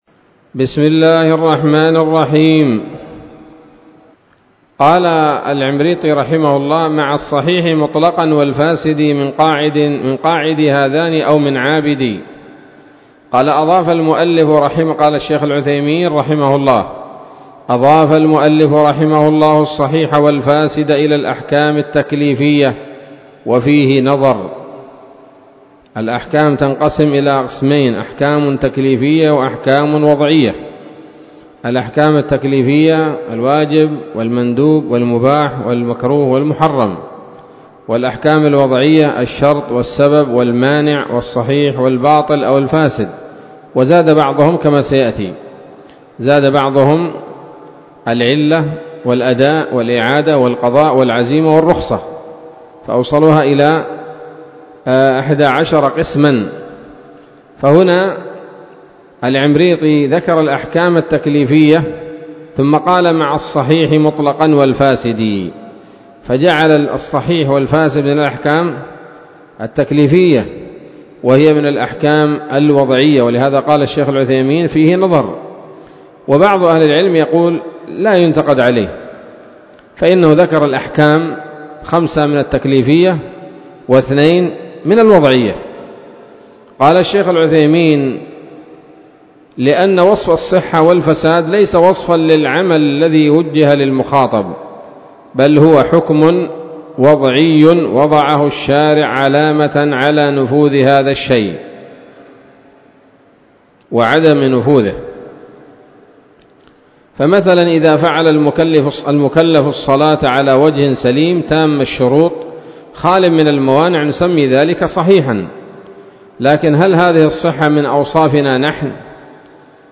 الدرس الثامن من شرح نظم الورقات للعلامة العثيمين رحمه الله تعالى